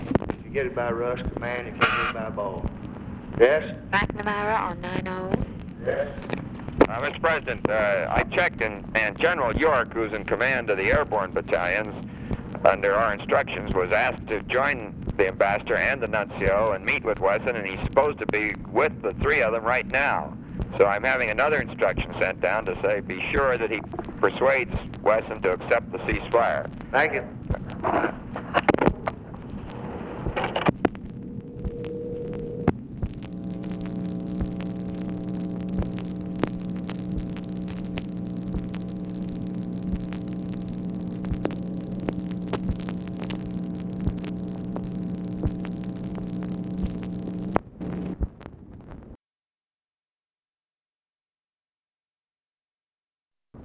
Conversation with ROBERT MCNAMARA and OFFICE CONVERSATION, April 30, 1965
Secret White House Tapes